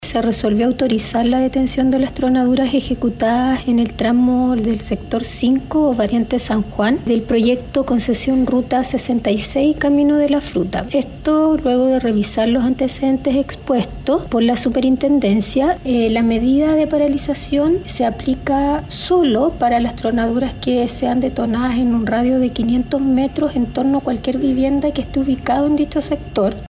Así lo comunicó Marcela Godoy, ministra de turno y presidenta del Segundo Tribunal Ambiental.